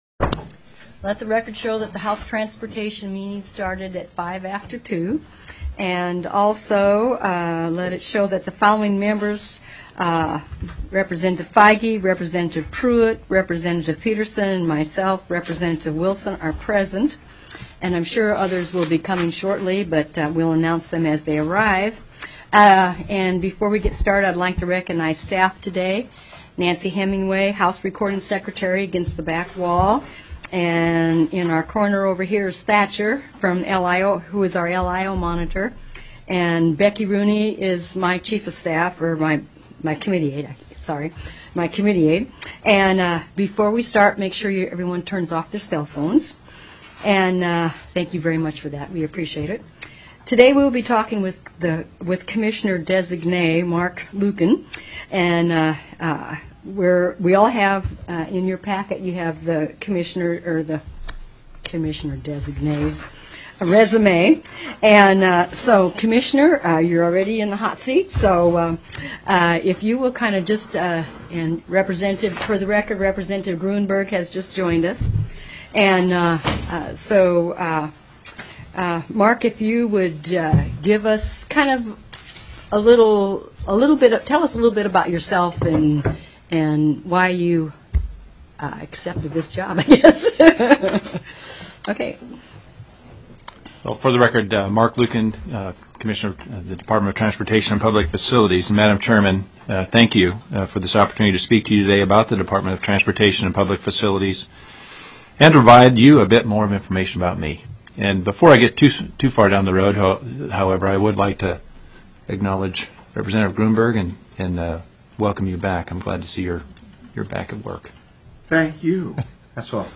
Confirmation Hearing: Commissioner, Department of Transportation & Public Facilities, Marc Luiken
POSITION STATEMENT: Testified and answered questions during the confirmation hearing.